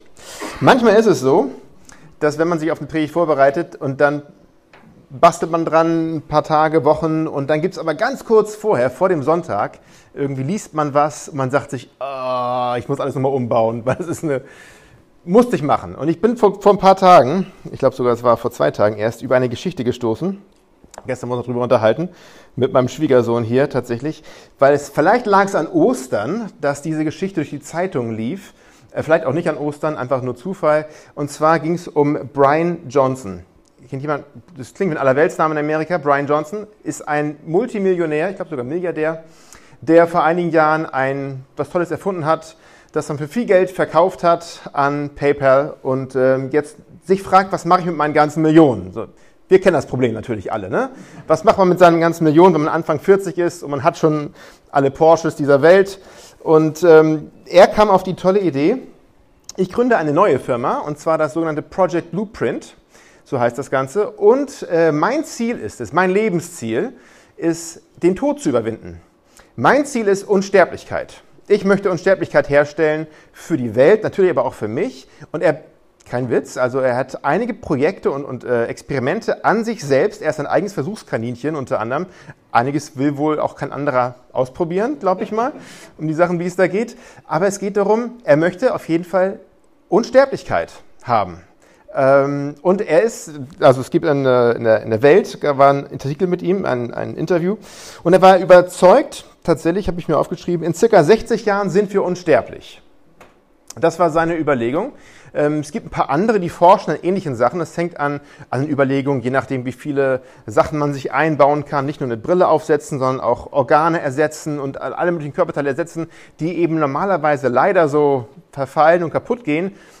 Serie: Standortgottesdienste